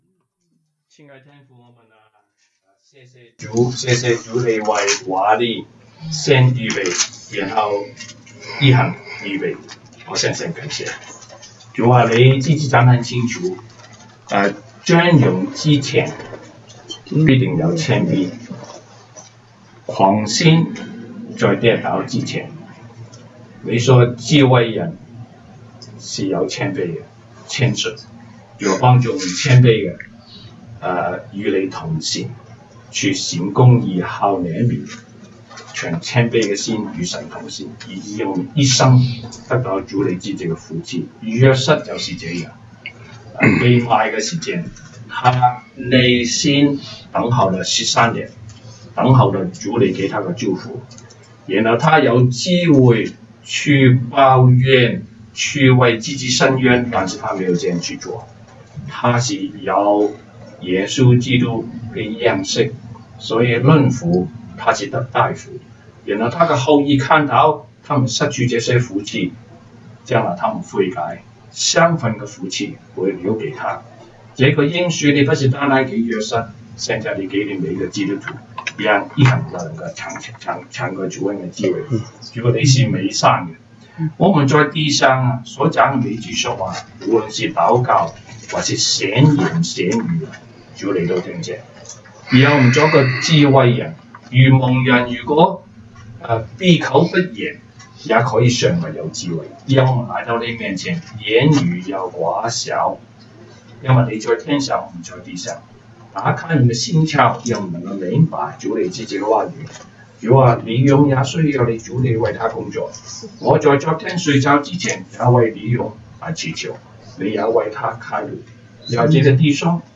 Service Type: 週一國語研經 Monday Bible Study